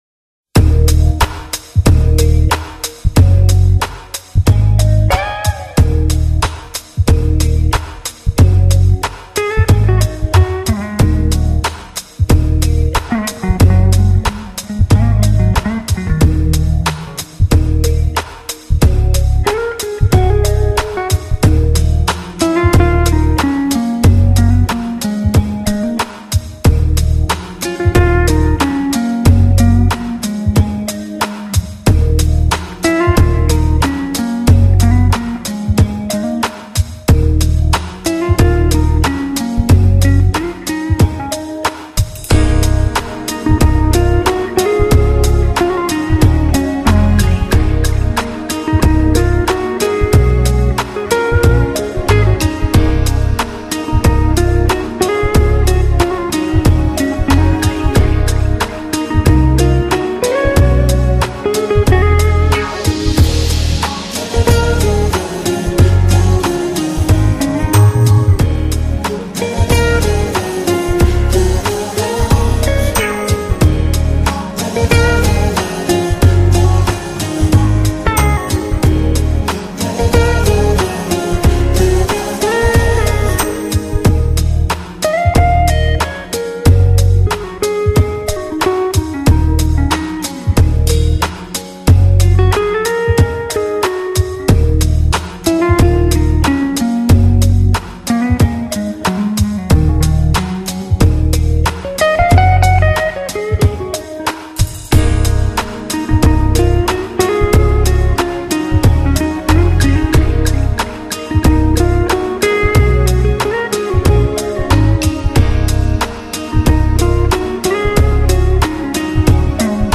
funky and sensuous
slinky overdubbed harmonies